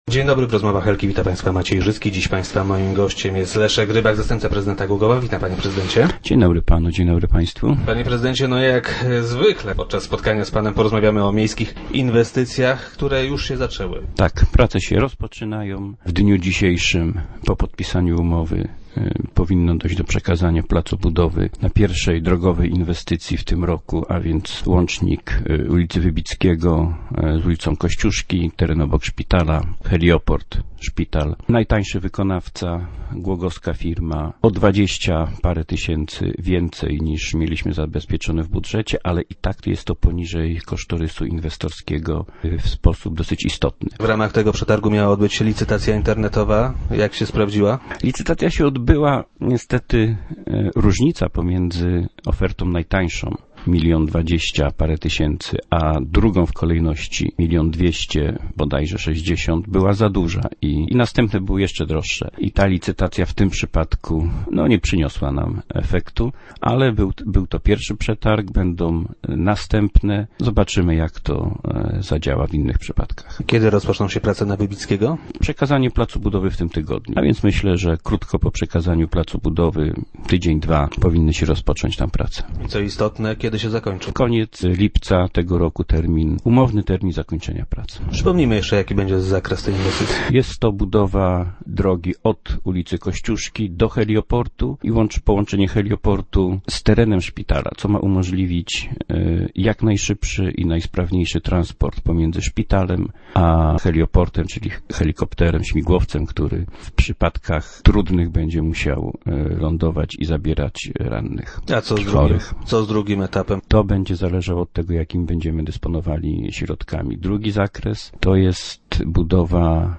- W tej chwili podpisujemy umowę na aktualizację dokumentacji dotyczącej tej inwestycji - mówił na radiowej antenie wiceprezydent Leszek Rybak, który był gościem Rozmów Elki.